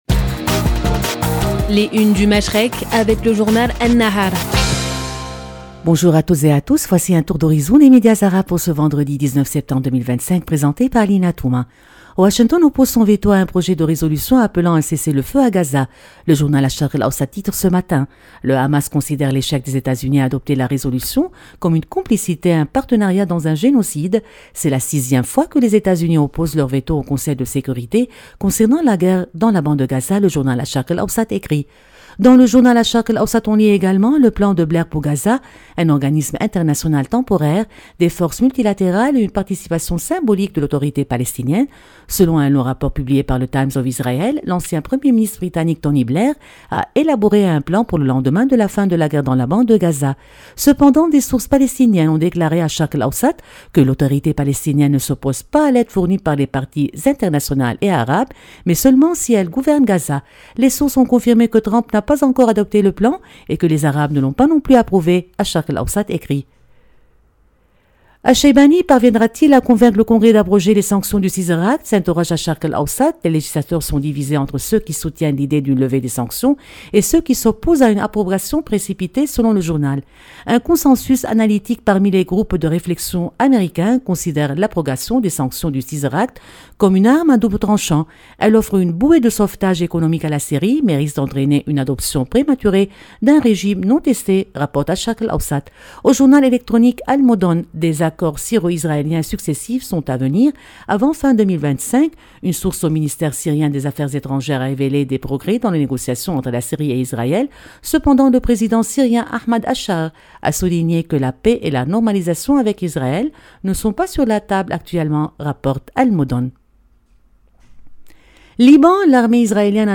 Revue de presse des médias arabes du 19/09/2025